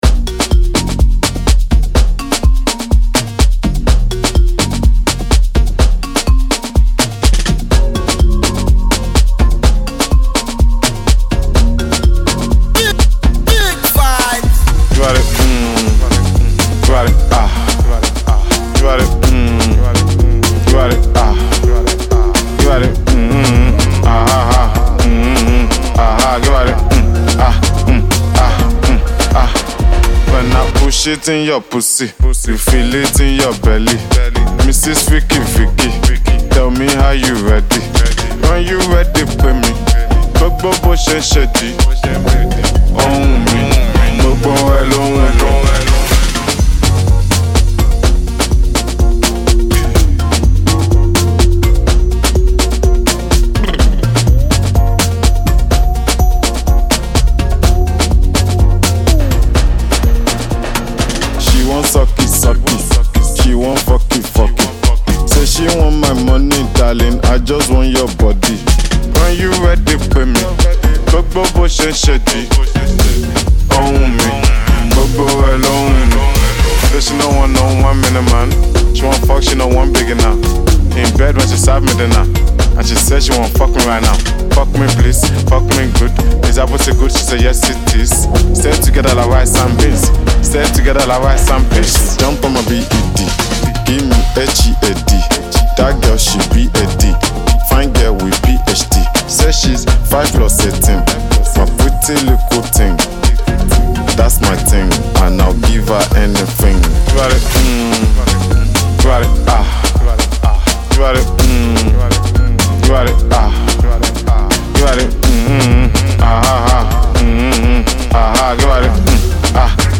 infectious rhythm